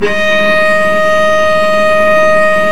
Index of /90_sSampleCDs/Roland L-CD702/VOL-1/STR_Vc Marc&Harm/STR_Vc Harmonics